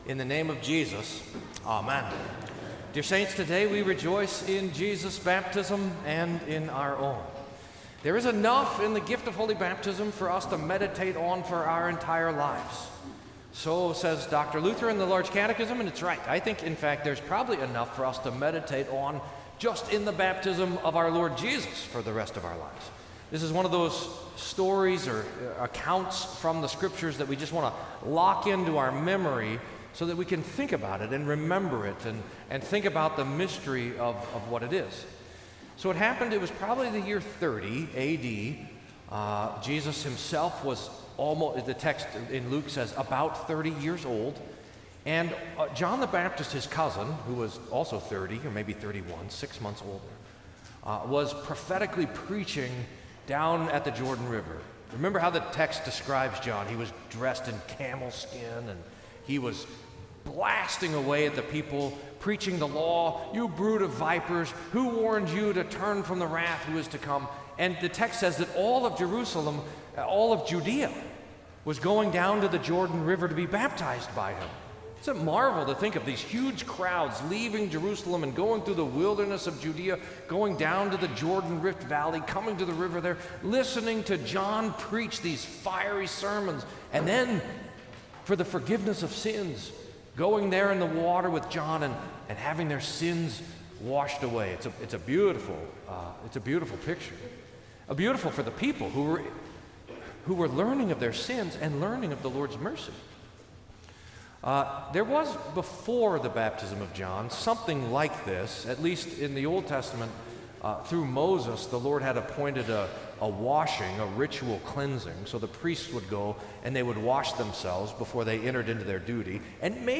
Sermon for the Baptism of Our Lord